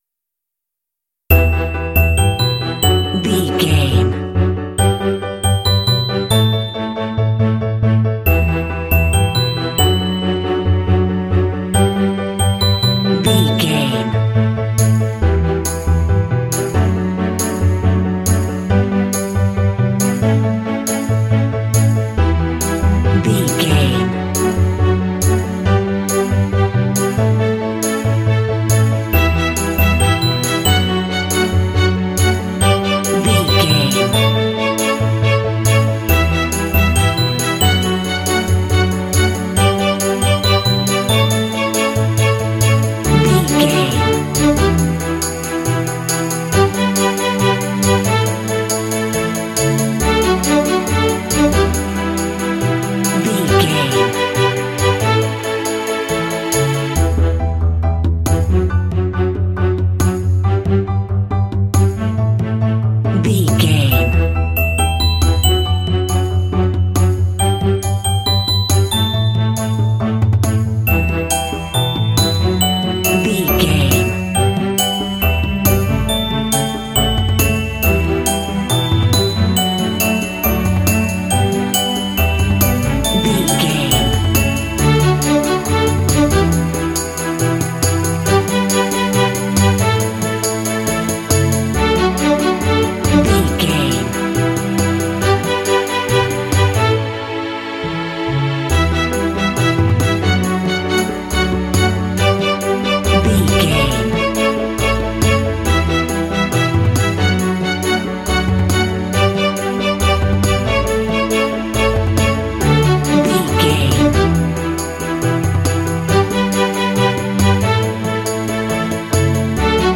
Fun and cheerful indie track with bells.
Uplifting
Ionian/Major
D
optimistic
bright
percussion
pop
symphonic rock